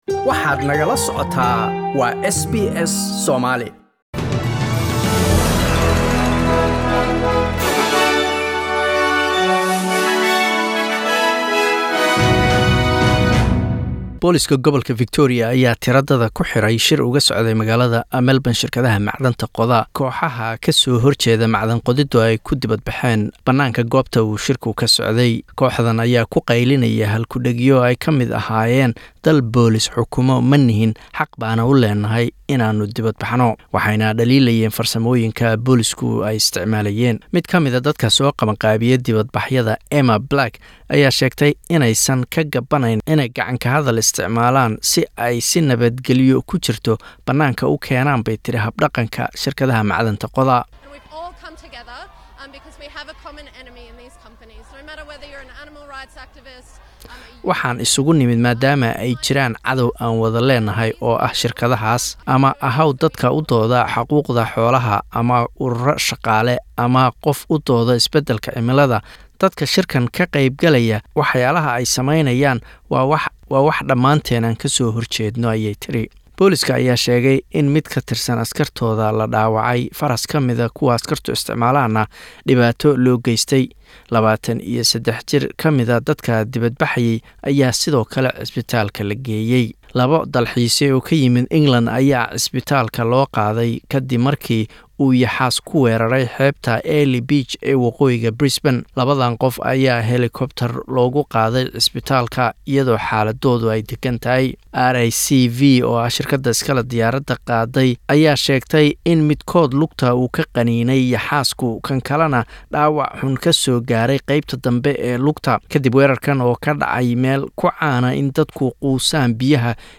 SBS News in Somali Tuesday 29/10